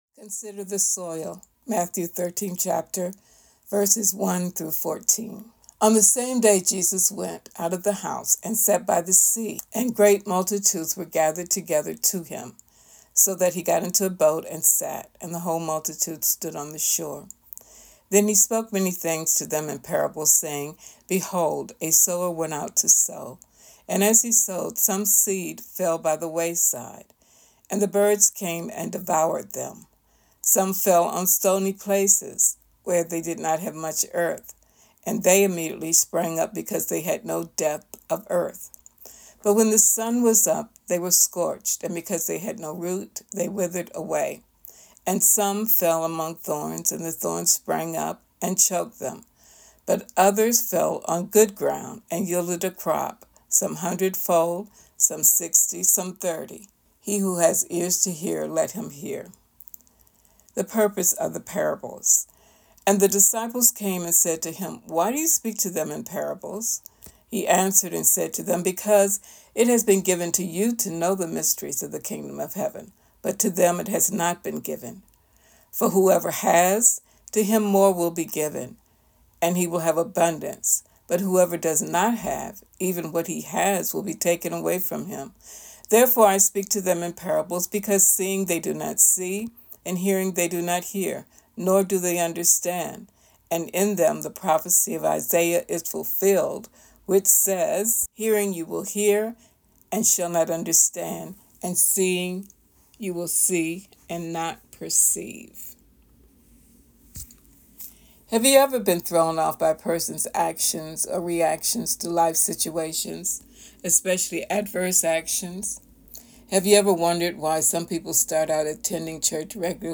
Download Teaching: Consider The Soil